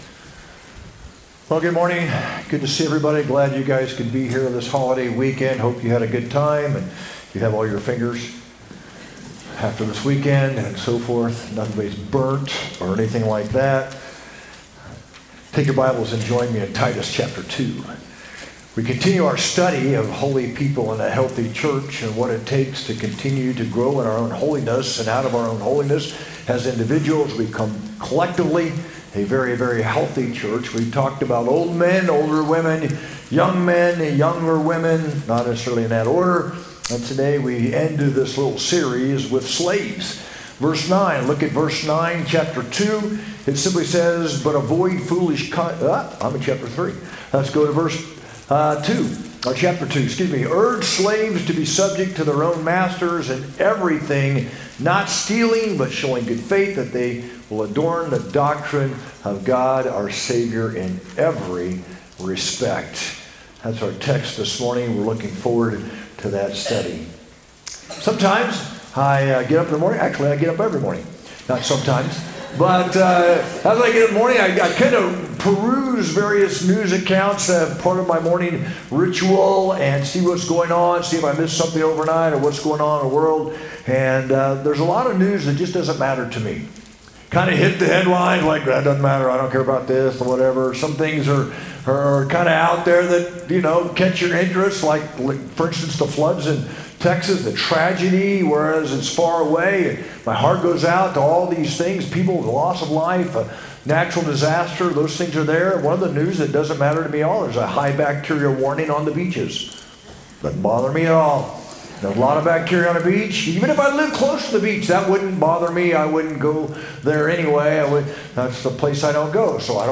sermon-7-6-25.mp3